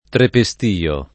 trepestio [ trepe S t & o ]